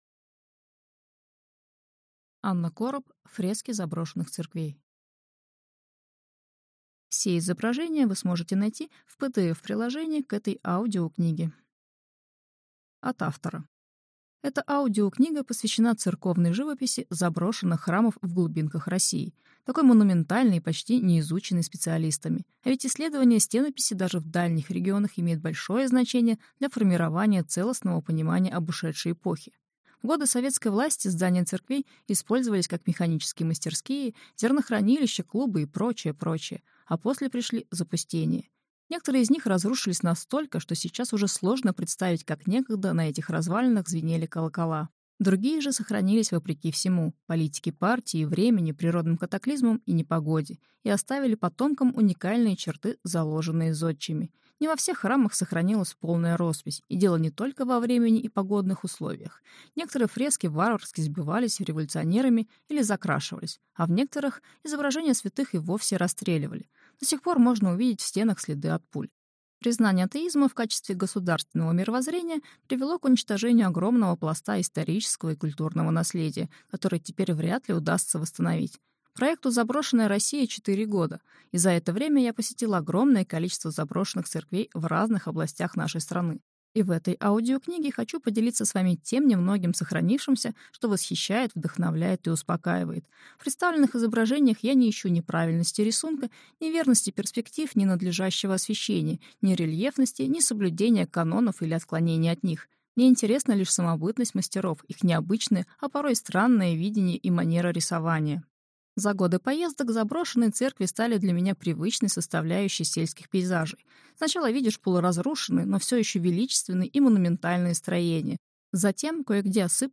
Аудиокнига Фрески заброшенных церквей | Библиотека аудиокниг